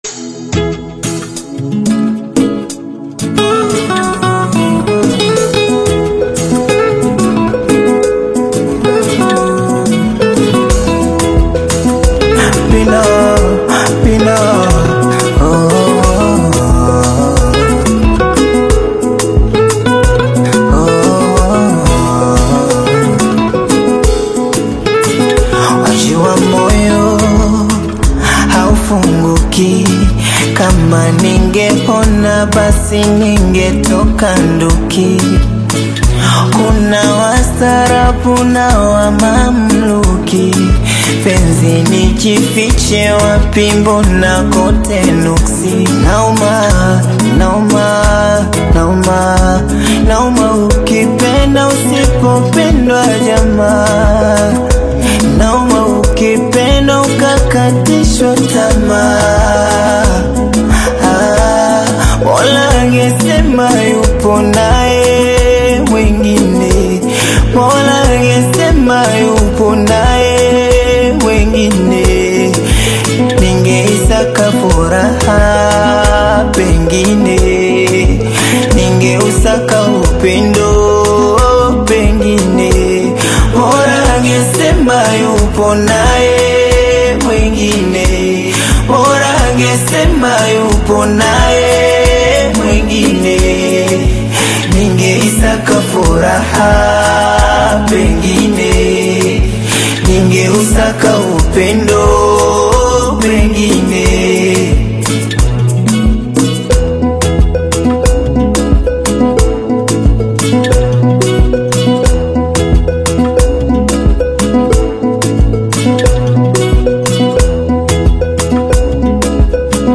thoughtful Afro-Pop/Bongo Flava track